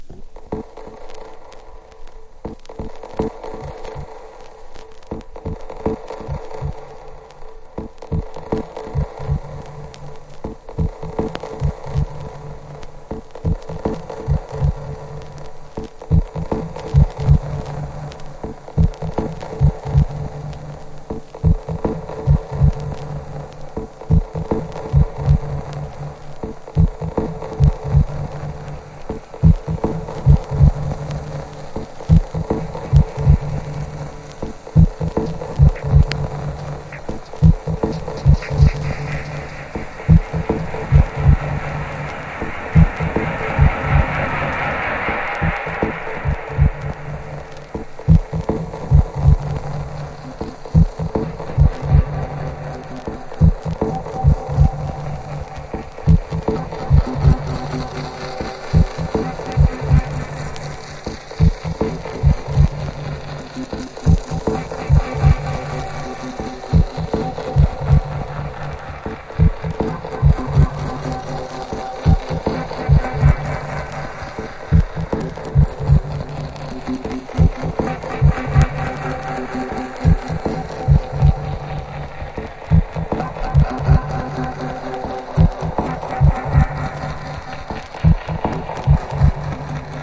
TECHNO / DETROIT / CHICAGO# DUB / LEFTFIELD